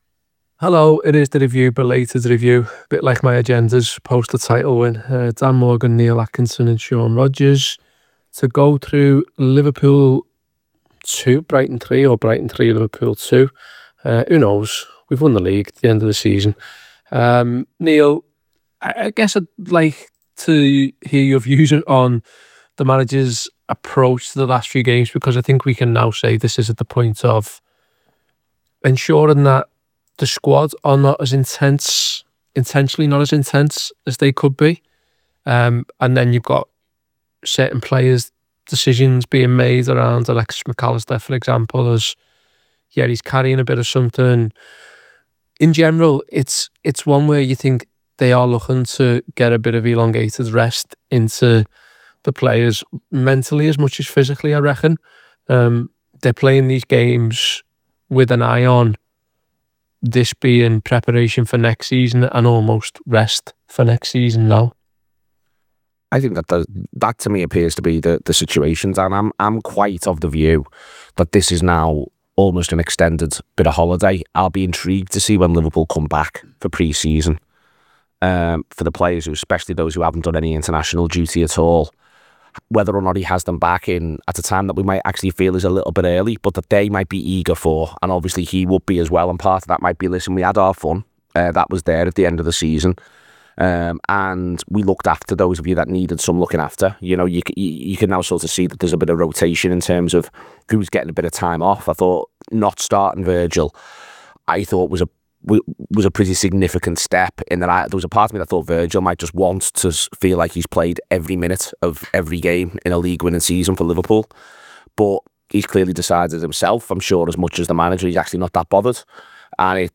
Below is a clip from the show – subscribe to The Anfield Wrap for more review chat around Brighton 3 Liverpool 2…